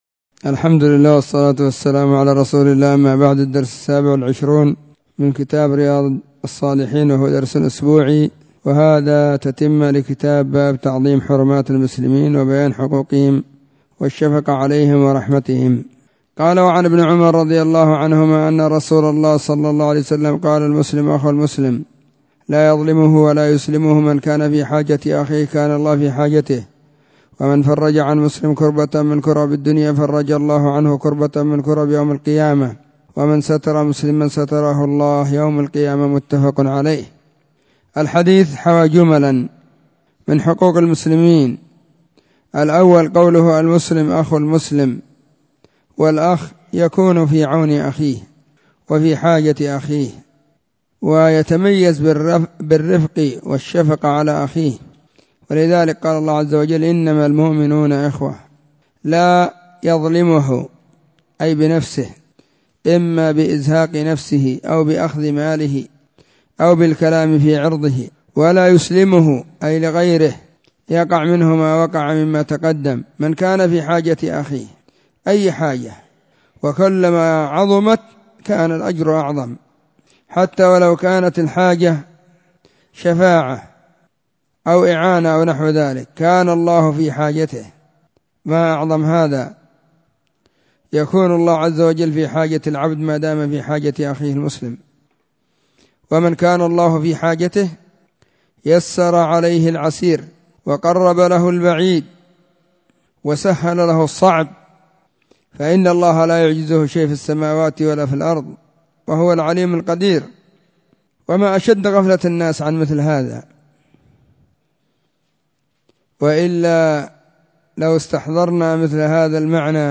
🕐 [بين المغرب والعشاء في كل يوم الخميس] 📢 مسجد الصحابة – بالغيضة – المهرة، اليمن حرسها الله.